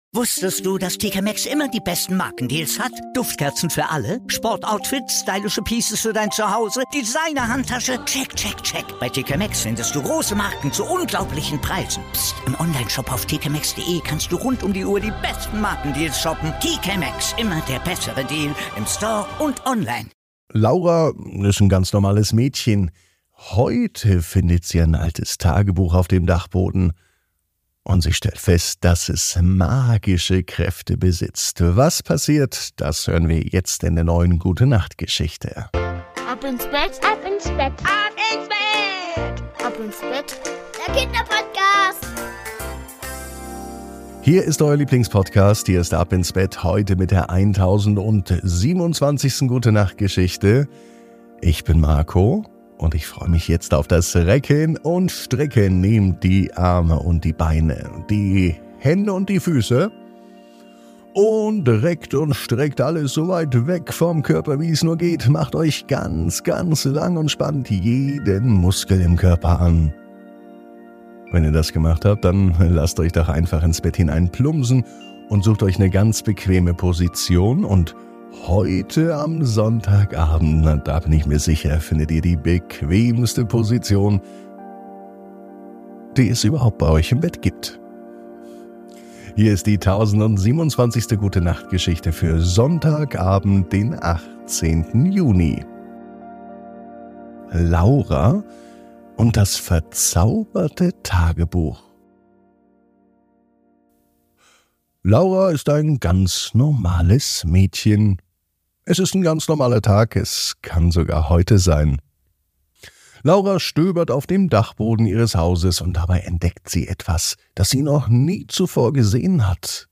Die Gute Nacht Geschichte für Sonntag